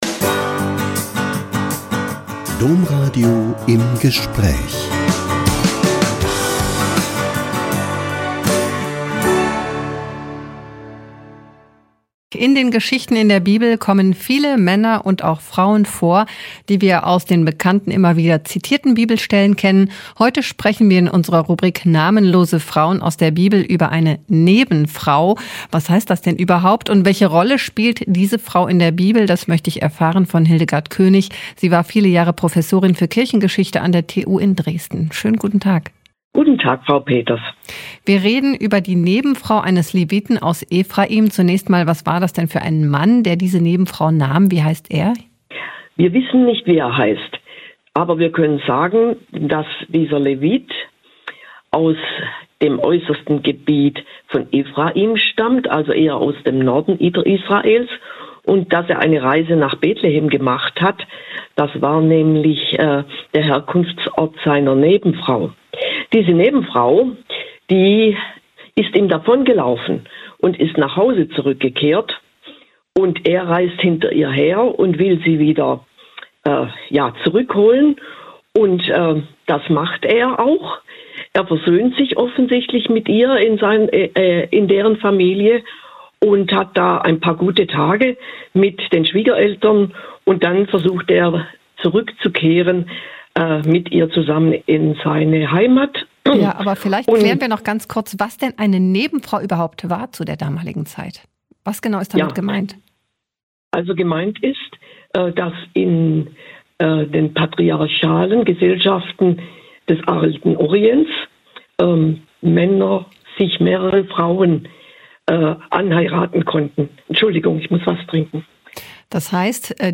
Blick auf namenlose Frauen in der Bibel: Die Nebenfrau des Leviten - Ein Interview